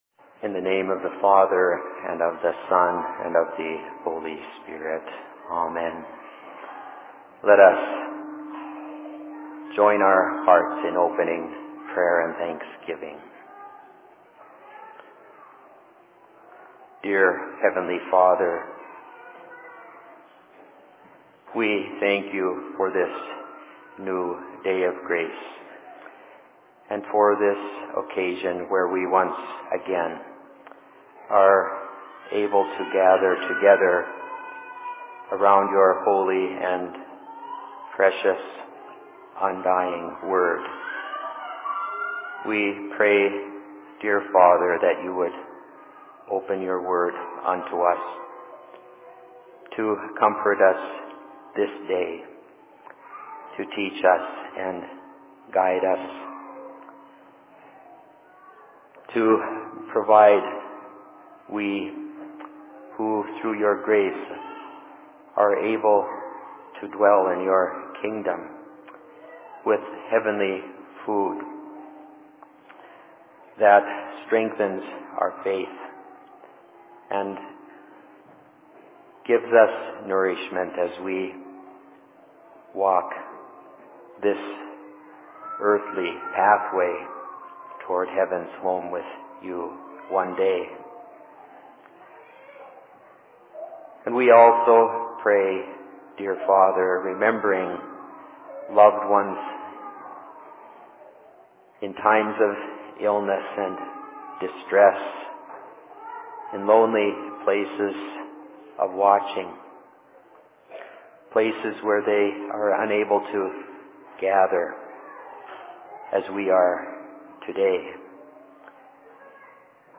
Sermon in Rockford 10.01.2010